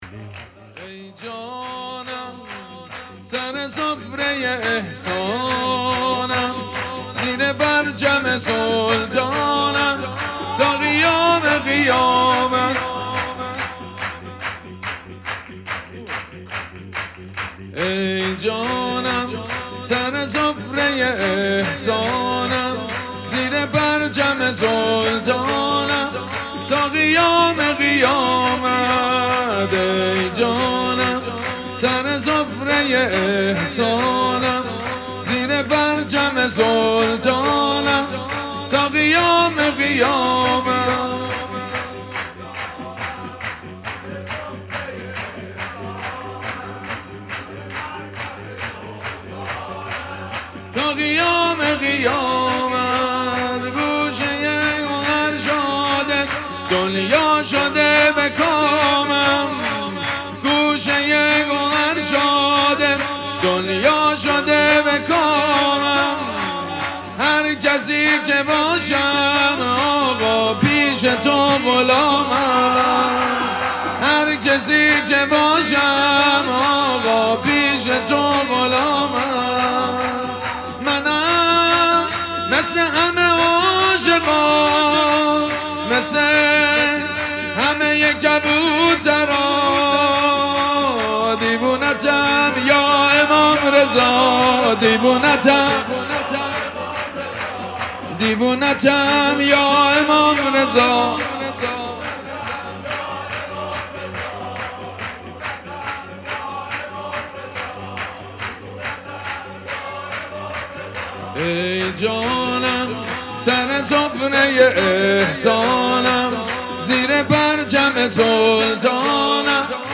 مراسم جشن ولادت امام رضا علیه‌السّلام
حسینیه ریحانه الحسین سلام الله علیها
سرود
مداح
حاج سید مجید بنی فاطمه